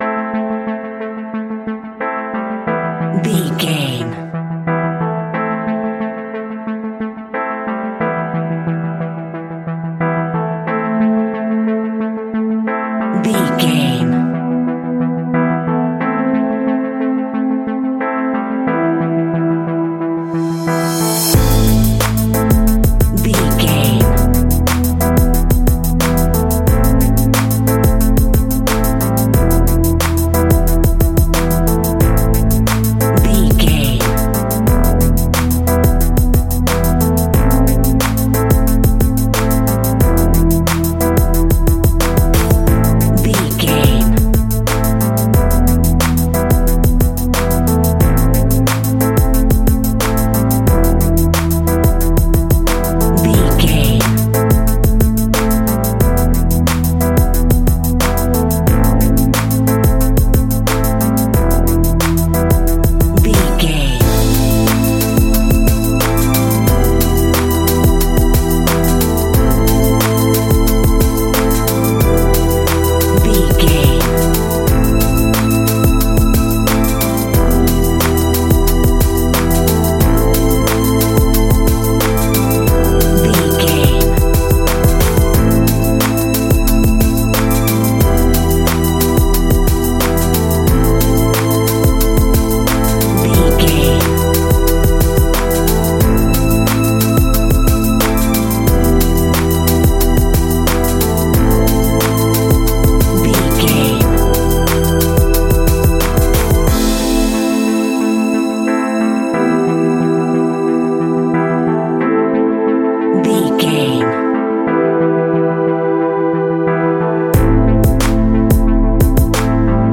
Aeolian/Minor
hip hop
hip hop instrumentals
downtempo
synth lead
synth bass
synth drums
turntables
hip hop loops